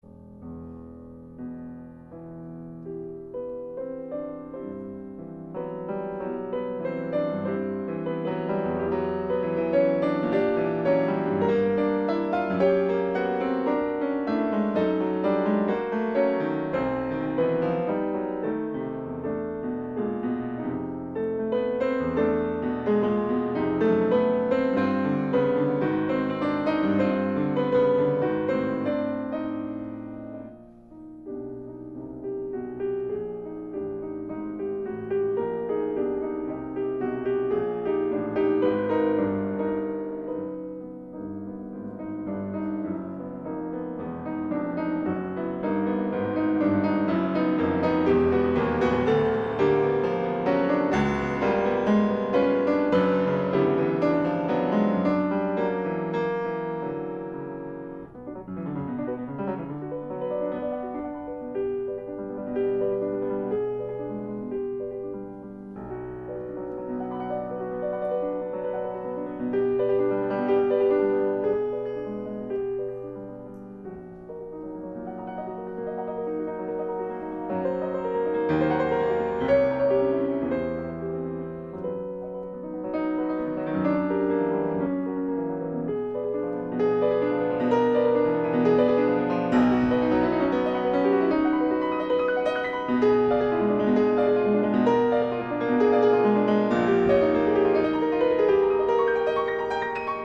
Constante y adecuada utilización de la pieza para piano Prélude, choral et fuge de César Franck para una película bautizada en su título original con el encabezamiento del poema Le ricordanze de Giacomo Leopardi,